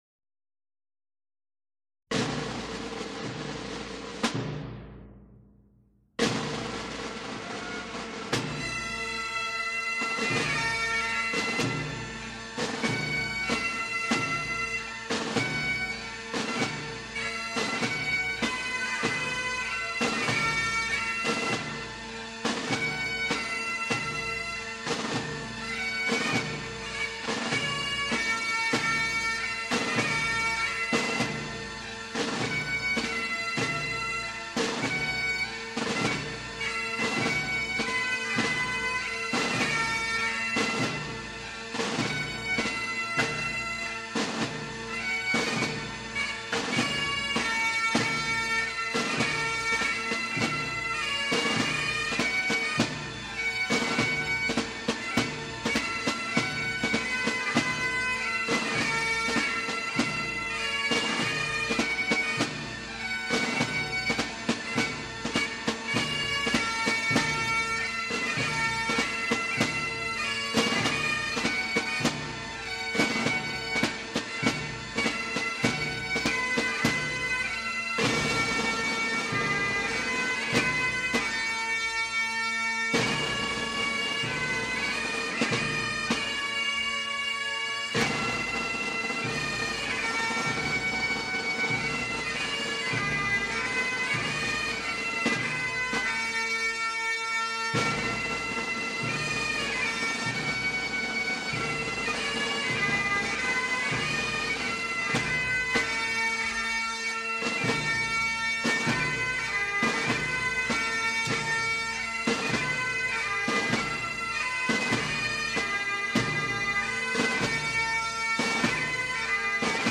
0083-风笛名曲起床号.mp3